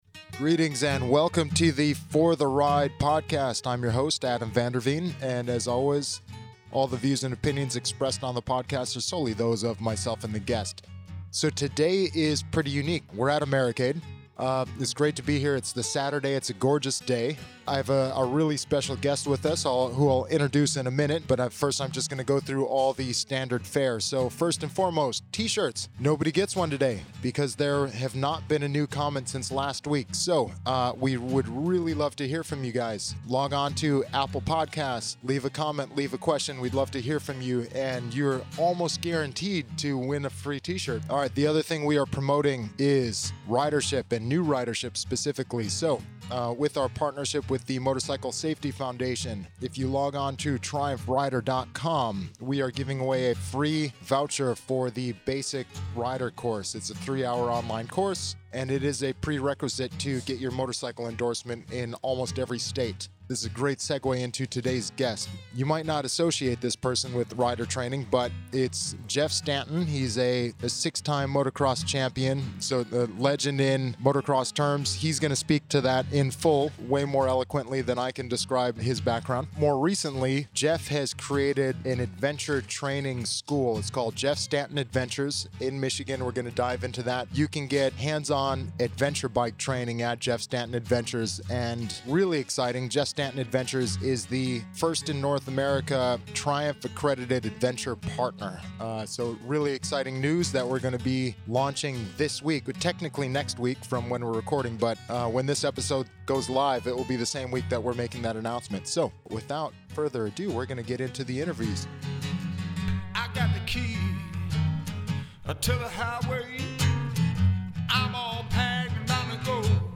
This interview, captured live at Americade, covers Jeff's raci...